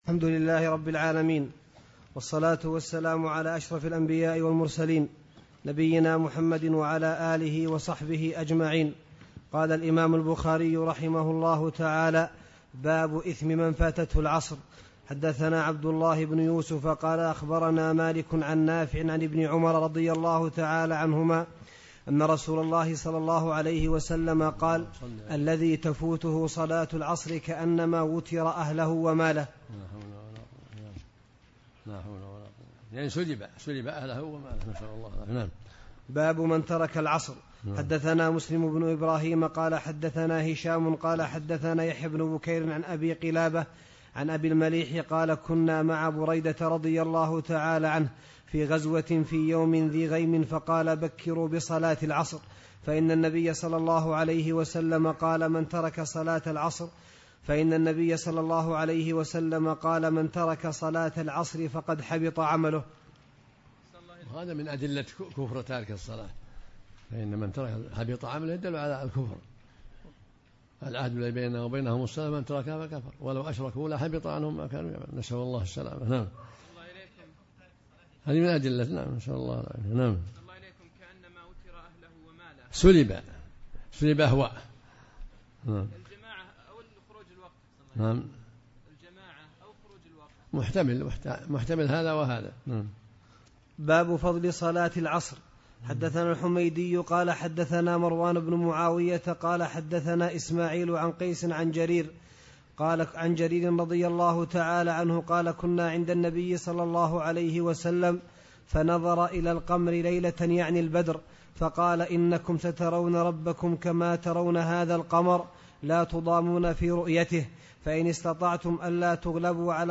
عبد العزيز بن باز - شروحات - الشريط رقم 39